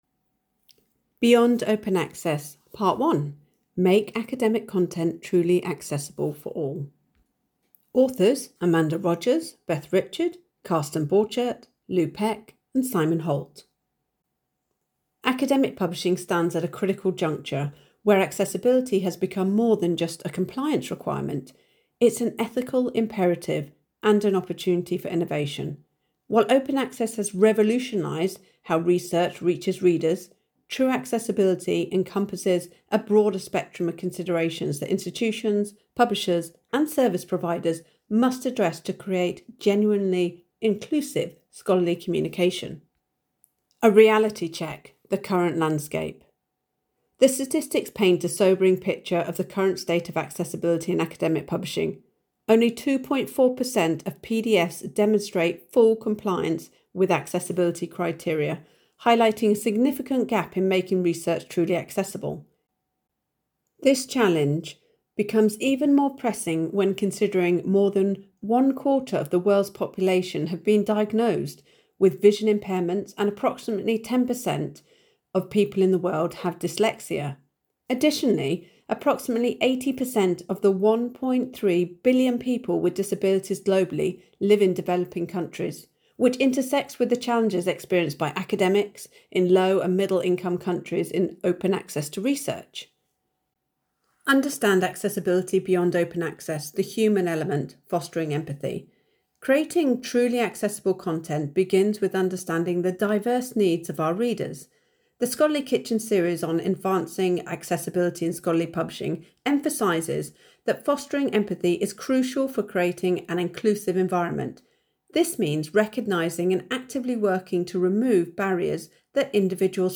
Authors’ note: The audio file linked below provides a spoken version of this blog post.